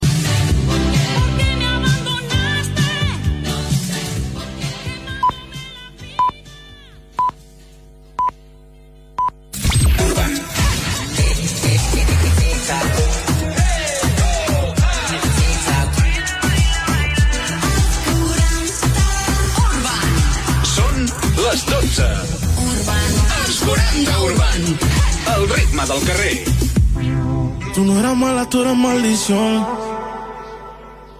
Últim tema musical de Radiolé a Barcelona, senyals horaris, primer indicatiu de l'emissora a Barcelona Els 40 Urban, hora i tema musical.
FM